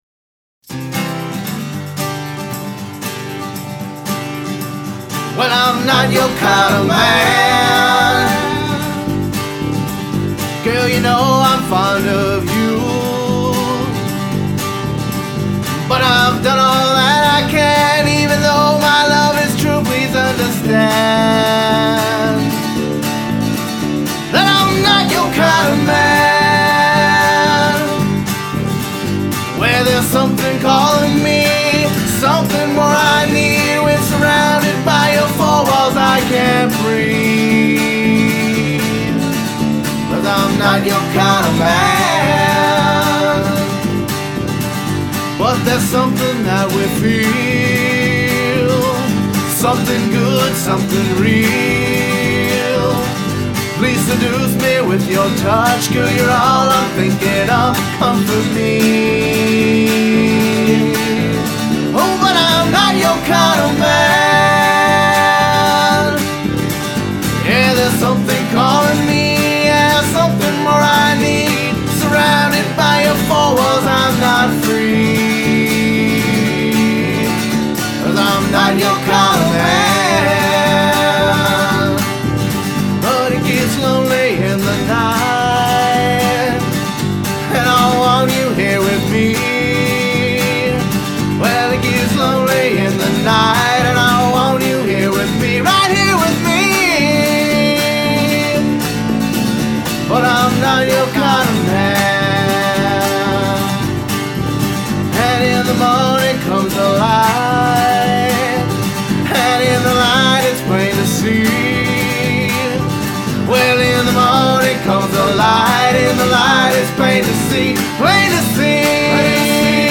Vocals, Guitars, Bass, Harmonica, Trumpet
Percussion